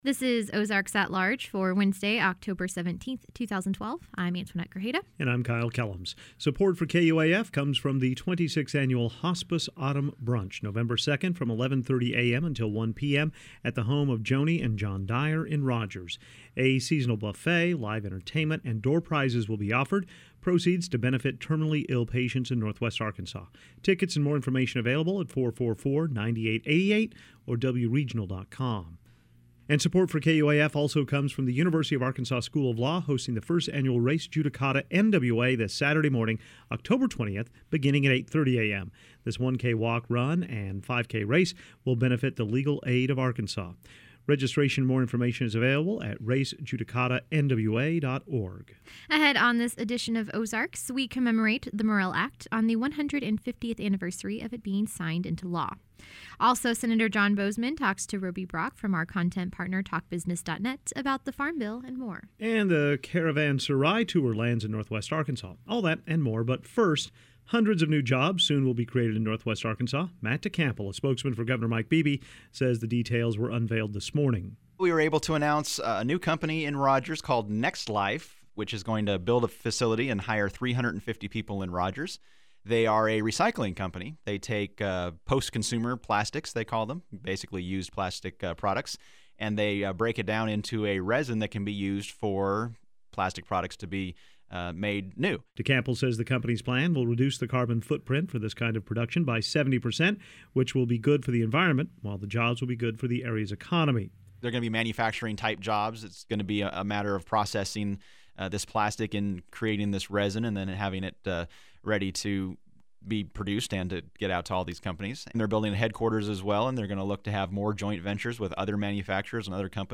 On this edition of Ozarks, we commemorate the 150th anniversary of the Morrill Act. Also, a conversation with Congressman John Boozman, and a preview of a Caravanserai performance at the Walton Arts